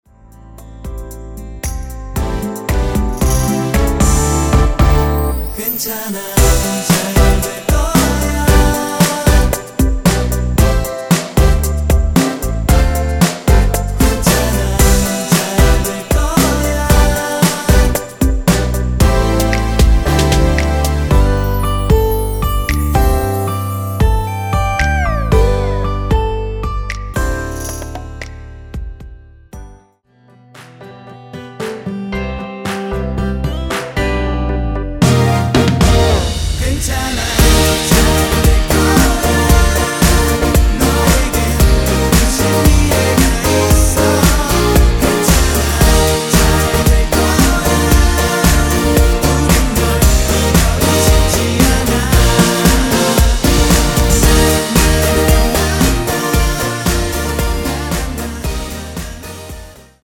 원키 코러스 포함된 MR입니다.(미리듣기 확인)
앞부분30초, 뒷부분30초씩 편집해서 올려 드리고 있습니다.